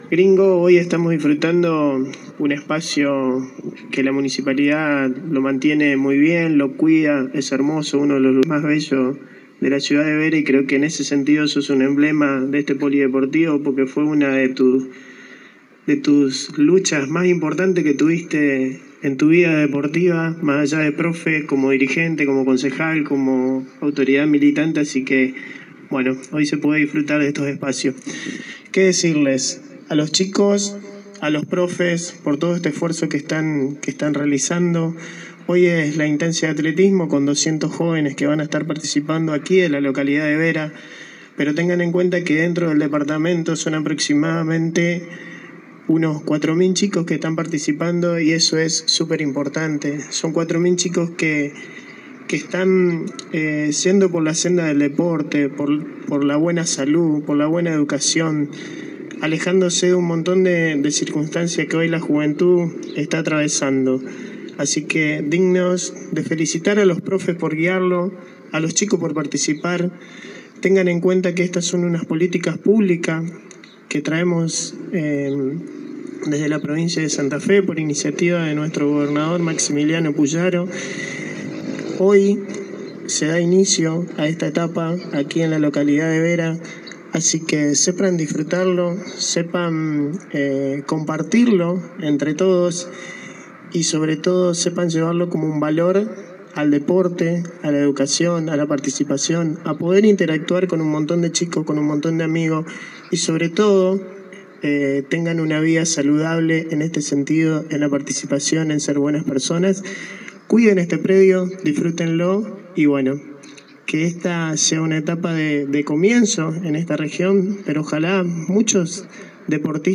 El acto de apertura tuvo lugar en el polideportivo de la Ciudad de Vera para la instancia local de las distintas diciplinas deportivas que contempla el programa.
Oscar Duarte – Director provincial zona norte